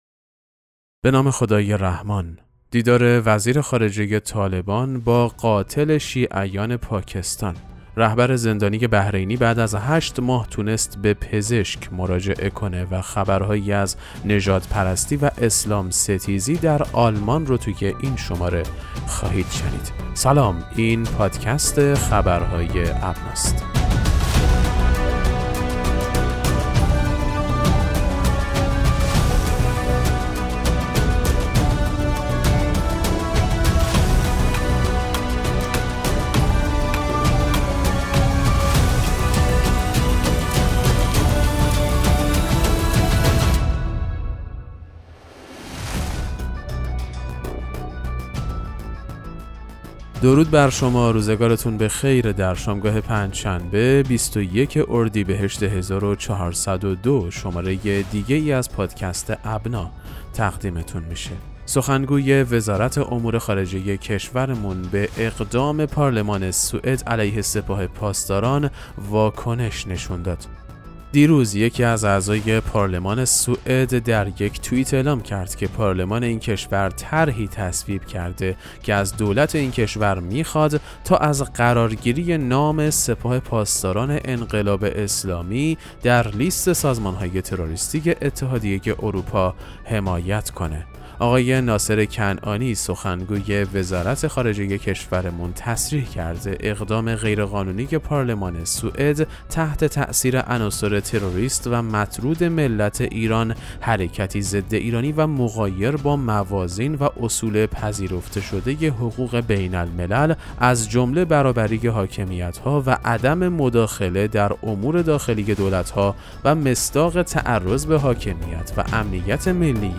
پادکست مهم‌ترین اخبار ابنا فارسی ــ 21 اردیبهشت 1402